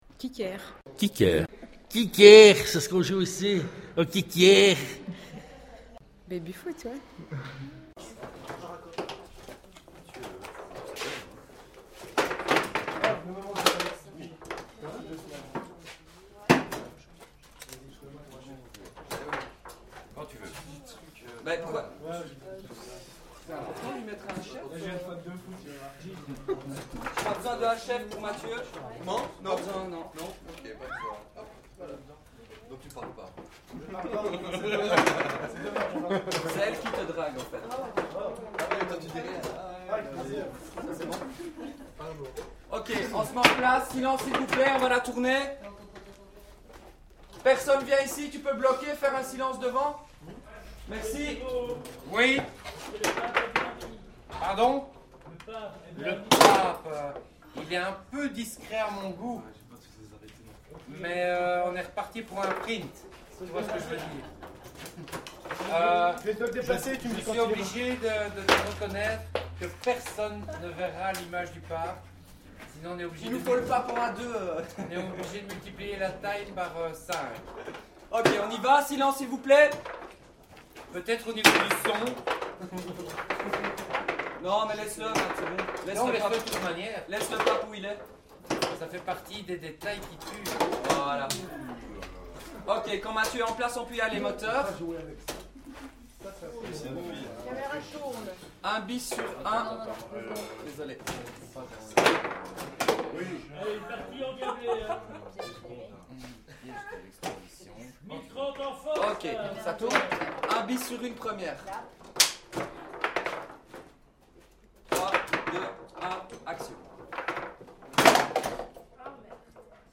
Filmset La Cage RPZT